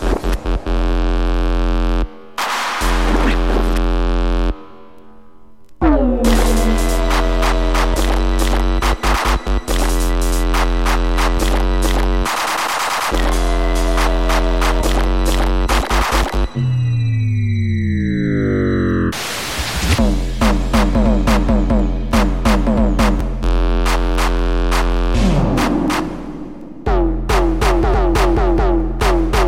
TOP >Vinyl >Grime/Dub-Step/HipHop/Juke
instrumental 試聴可能